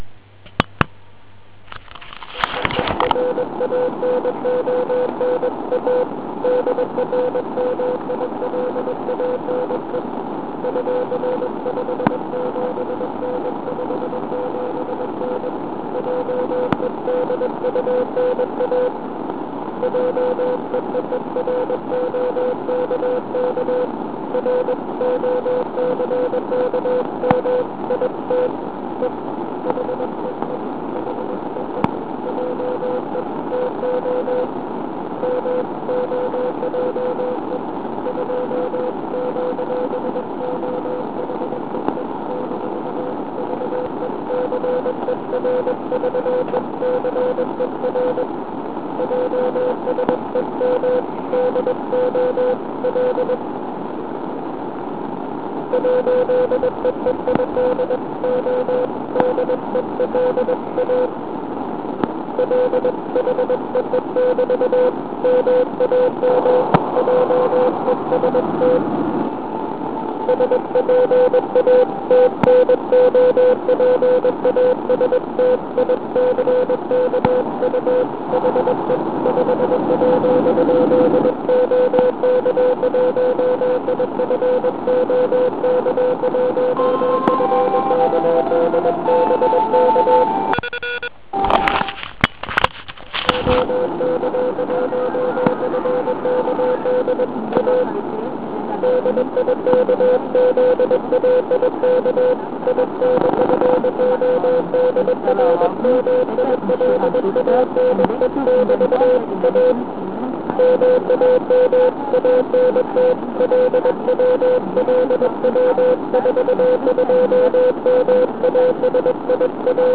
Jen ho dokončím, slyším na stejném kmitočtu krásný signálek.